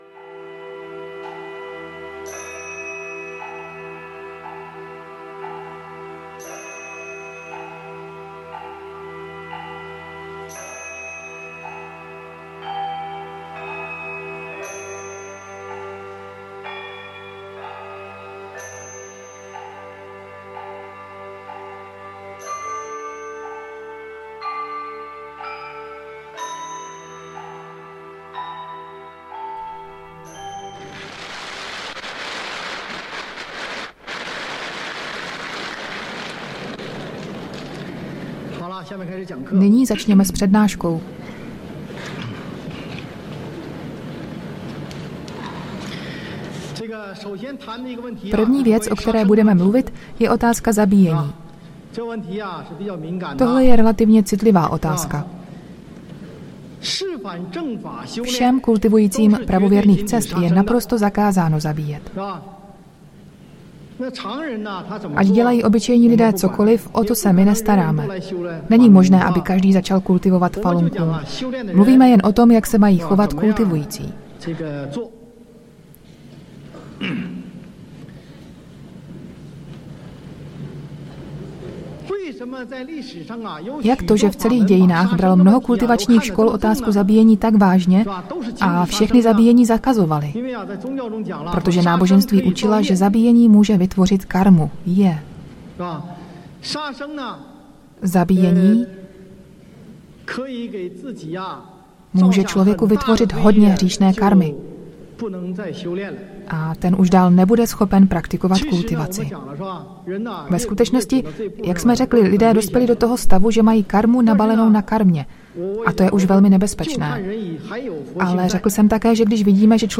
Prednáška 1